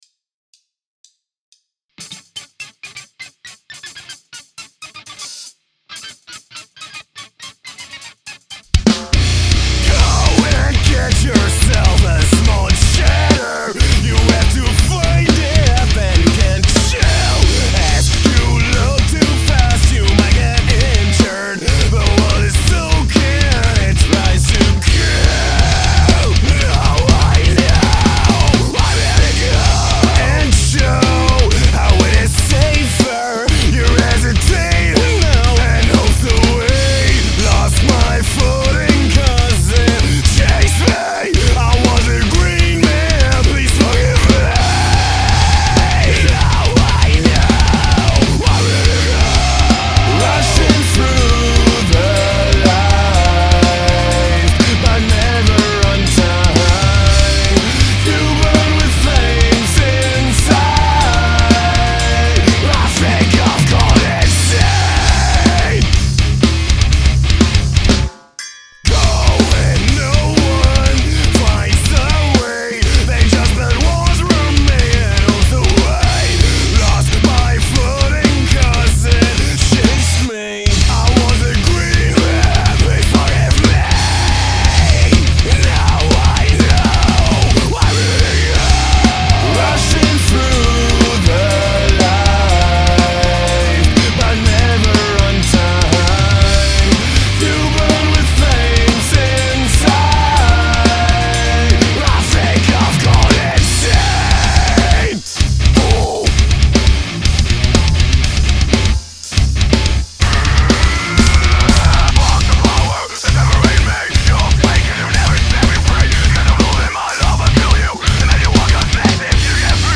Mein Pla-<er sacht, das ist übersteuert.